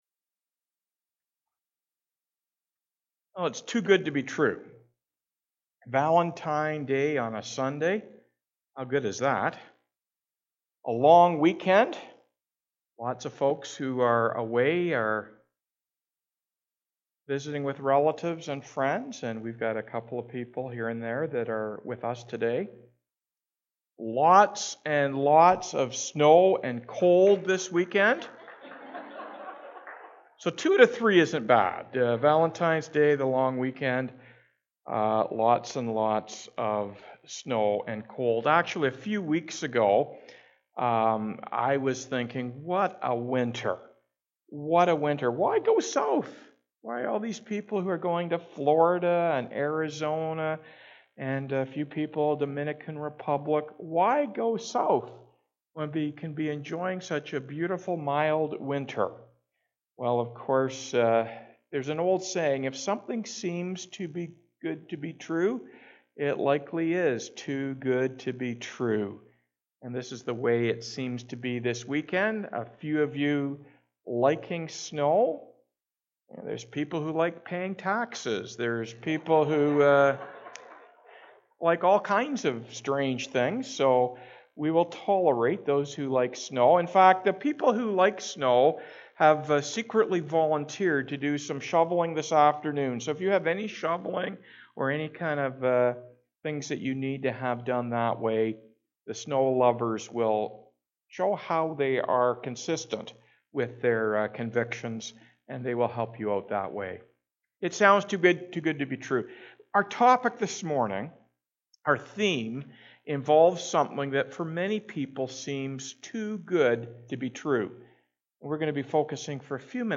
Weekly Sermons - Byron Community Church